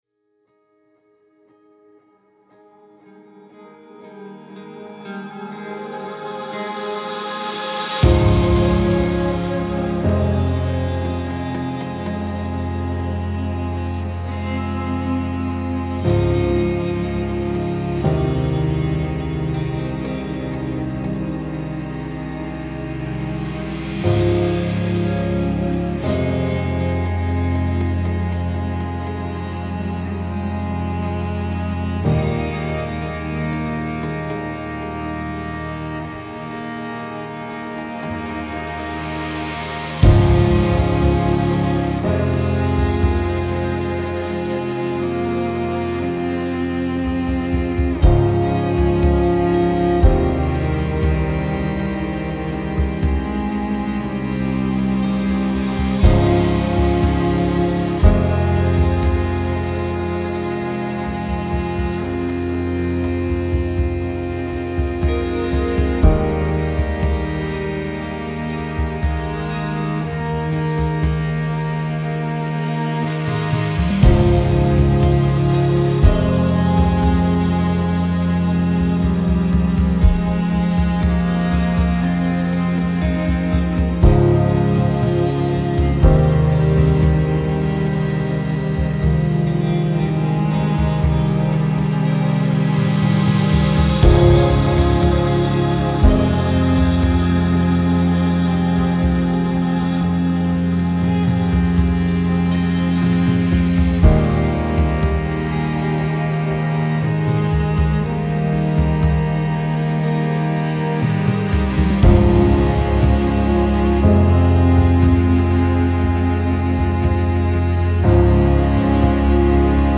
عمیق و تامل برانگیز
مدرن کلاسیک